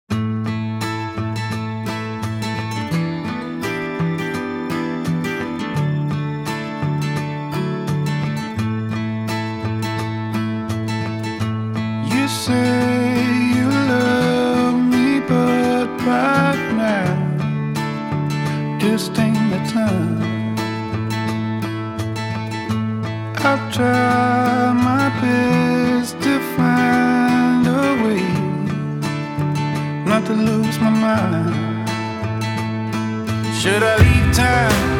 # Soft Rock